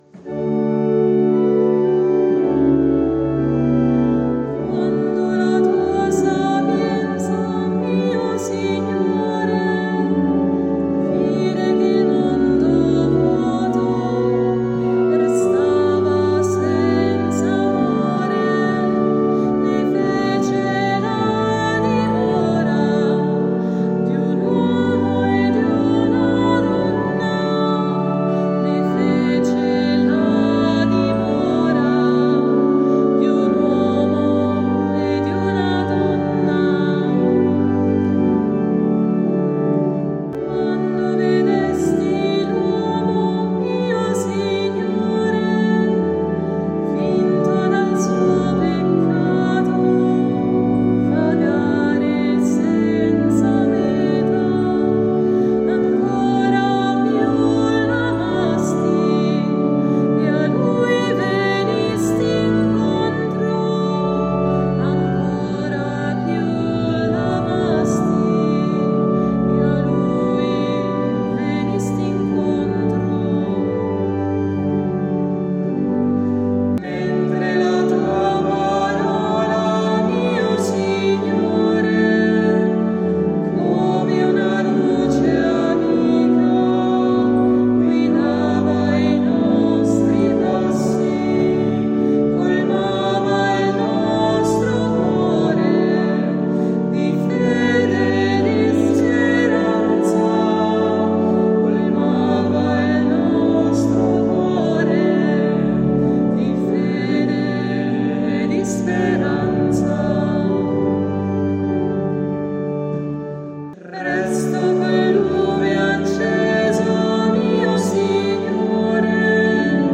Il controcanto può arricchire la voce dell’assemblea.